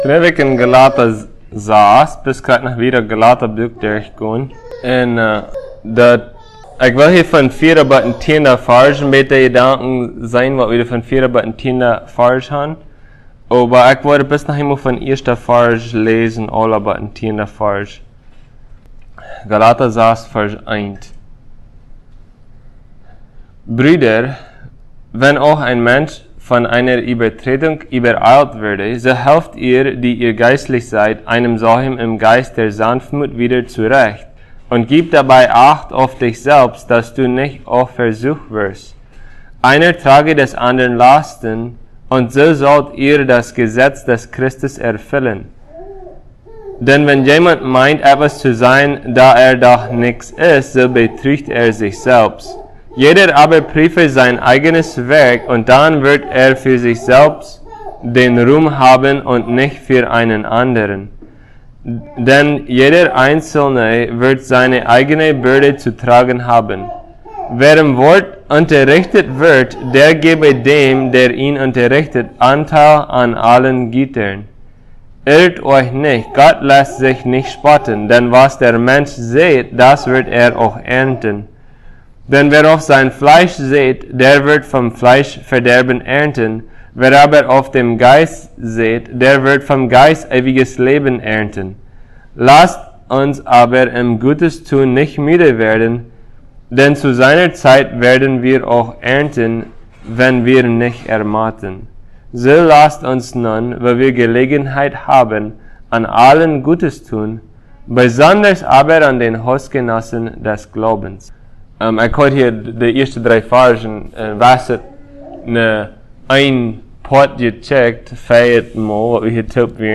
Passage: Galatians 6:4-10 Service Type: Sunday Plautdietsch